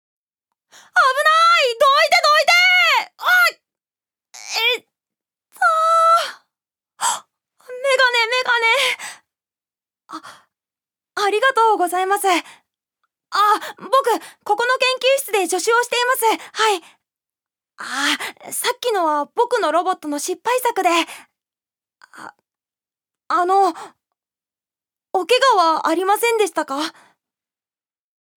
預かり：女性
セリフ３